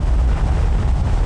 Platformboosterrising Sound Effect
platformboosterrising-2.mp3